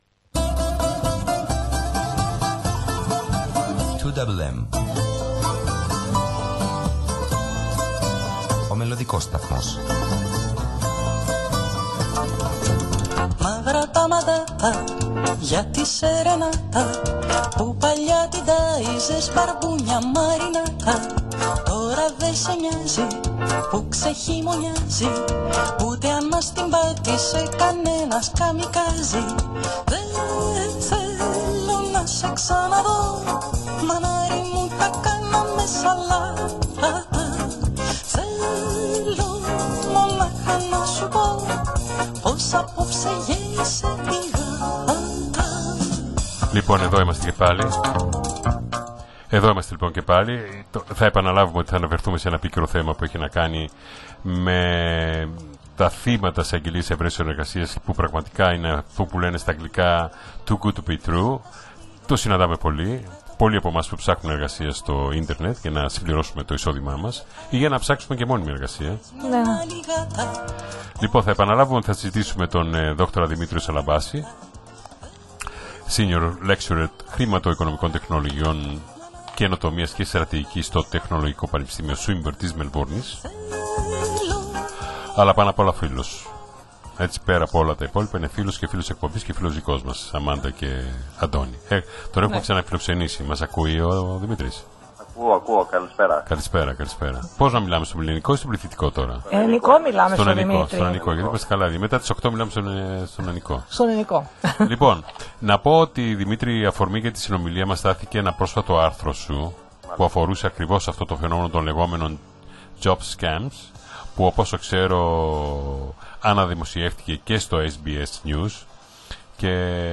συνέντευξη
ελληνόφωνο ραδιοσταθμό του Σίδνει